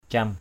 /cam/ ~ /ca:m/